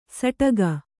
♪ saṭaga